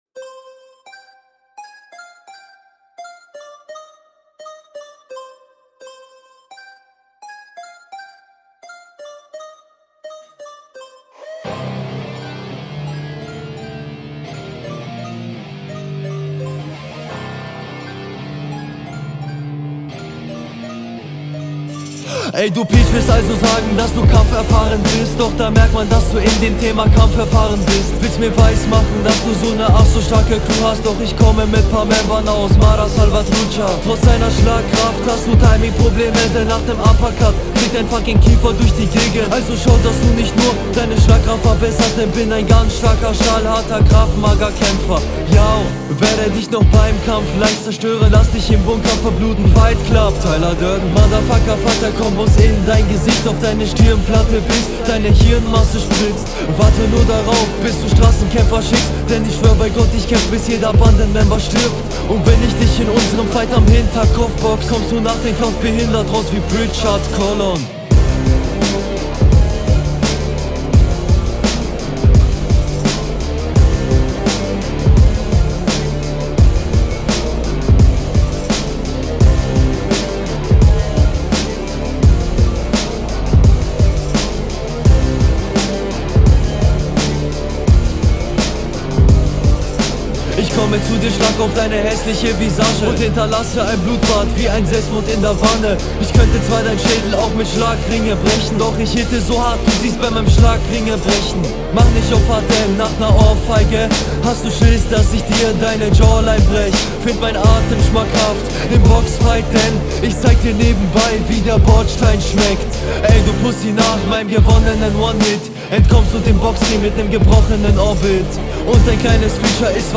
deine stimme geht ein wenig unter im Beat, Nasenflügel line gut gekontert, die lines vom …